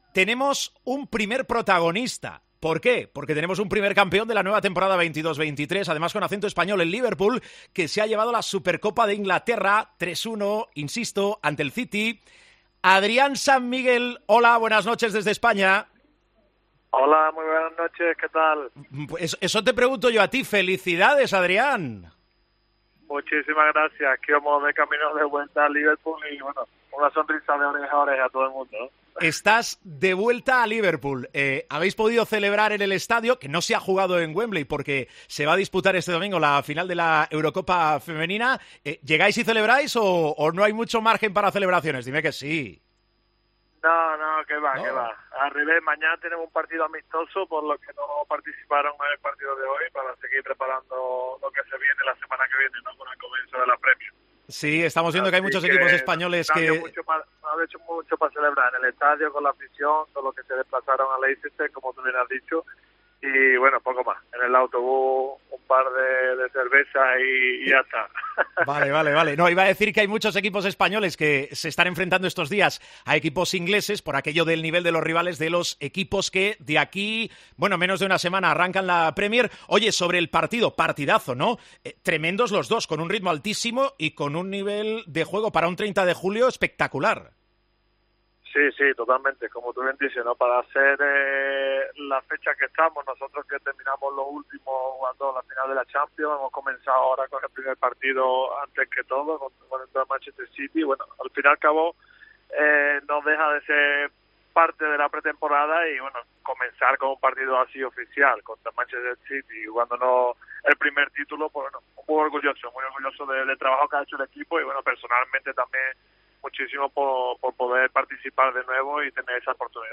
El portero español del Liverpool ha valorado en Tiempo de Juego el triunfo de los 'reds' contra el Manchester City en la Community Shield.